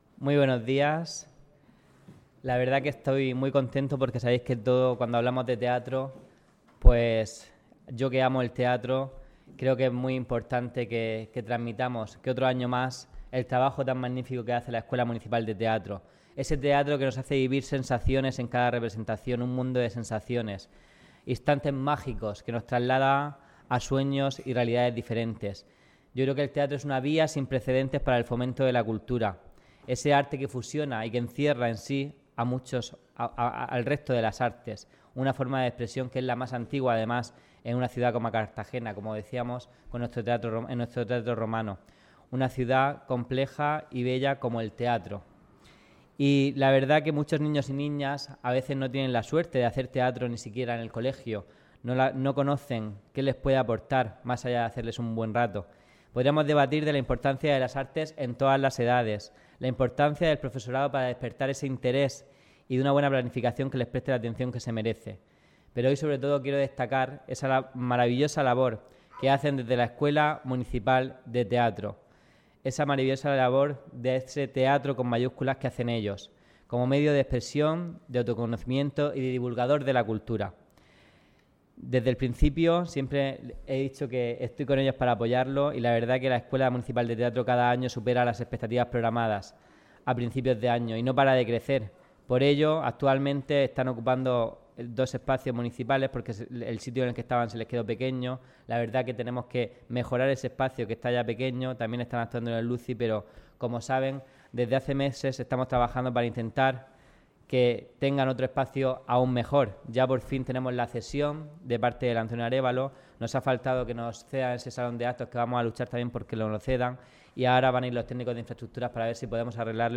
Audio: Presentaci�n Escuela Municipal de teatro Cartagena. Muestra de fin de curso 2017-2018 (MP3 - 9,30 MB)